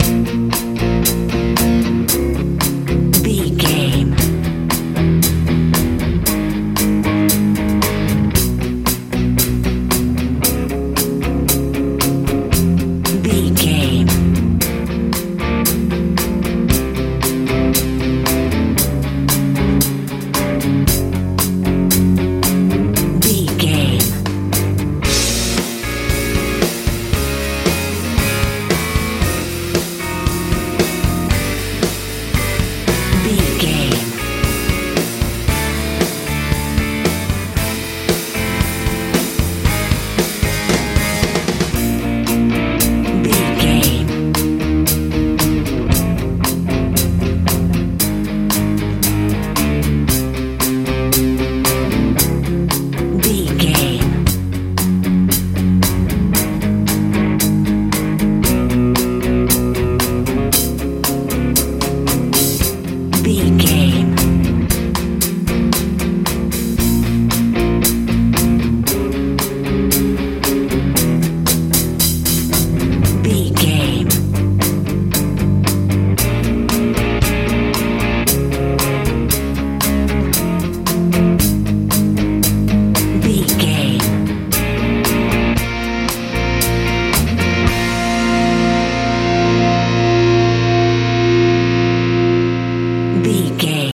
med rock feel
Ionian/Major
B♭
driving
energetic
electric guitar
bass guitar
drums
80s
90s